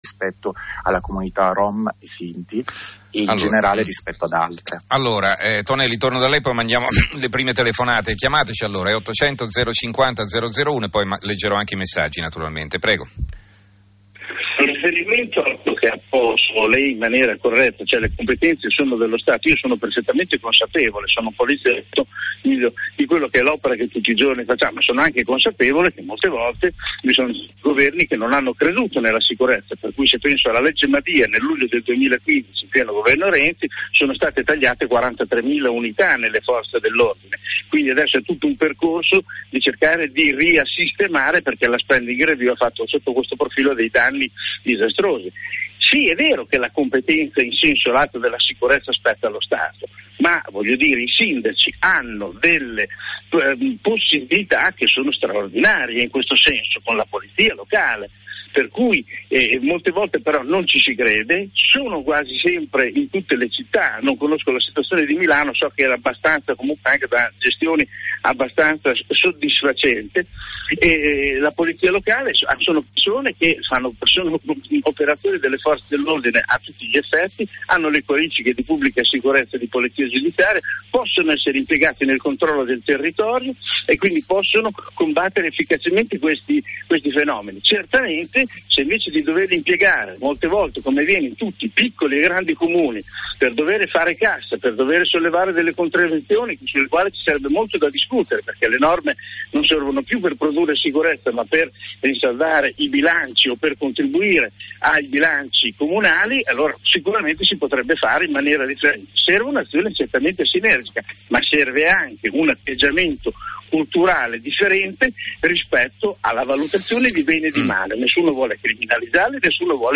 TONELLI OSPITE DI RAI RADIO 1 PARLA DI MICROCRIMINALITÀ DIFFUSA NEI MEZZI DI TRASPORTO DELLE GRANDI CITTÀ